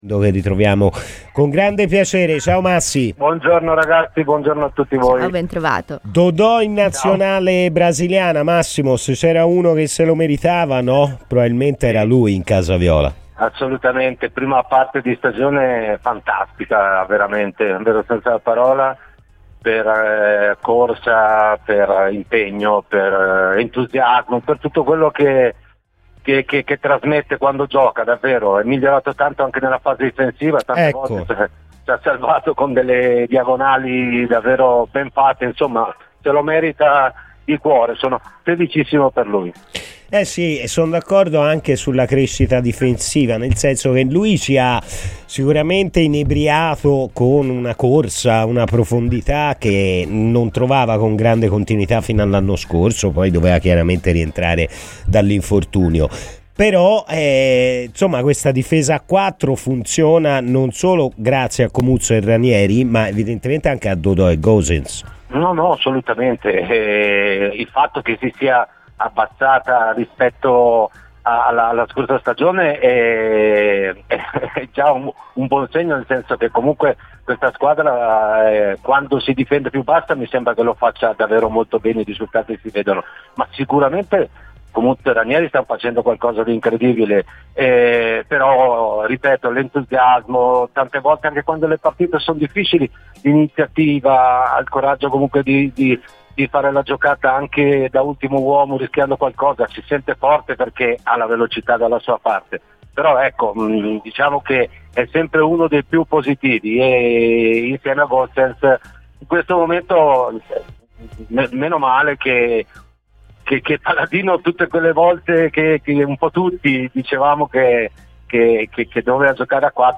L'ex centrocampista della Fiorentina Massimo Orlando è intervenuto ai microfoni di Radio FirenzeViola durante la trasmissione "Palla al centro":